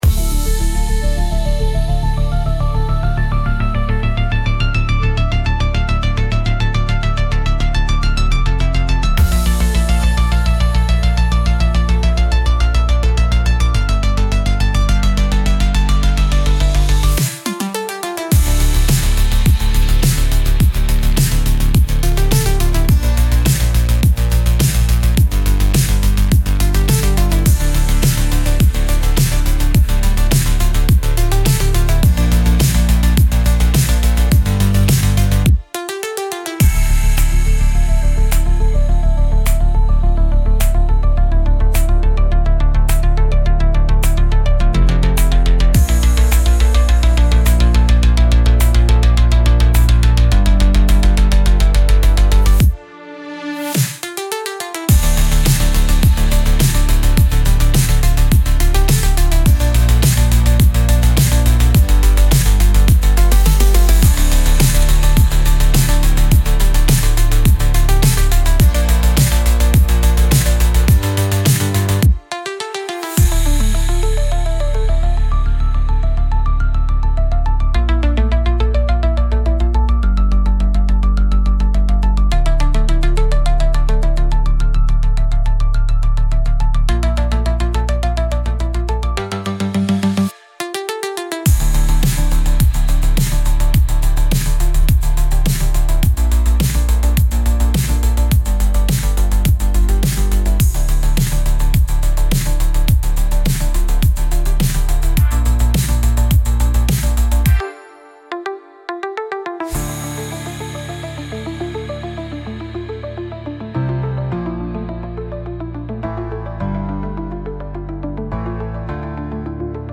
Instrumental -Real Liberty Media DOT xyz